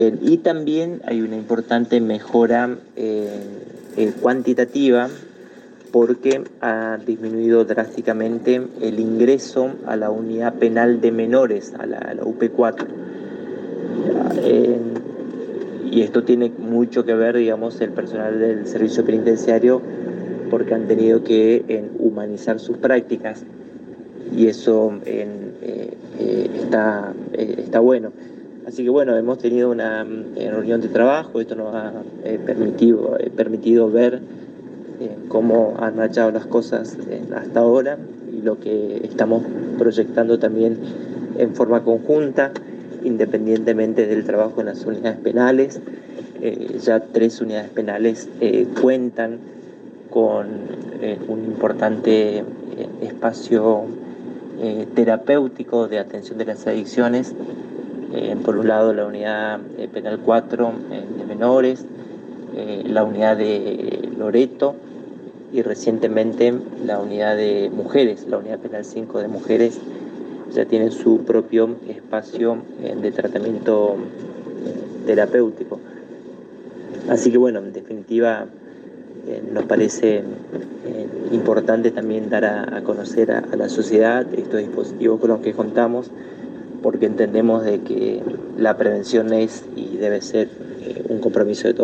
En diálogo exclusivo con la ANG el Ministro de Prevención de Adicciones y Control de Drogas Magister Samuel Lopez comentó sobre la reunión de trabajo y análisis de la gestión llevada a cabo por los Centros Modelos con el Alcaide Mayor Manuel Dutto Director General del Servicio Penitenciario Provincial.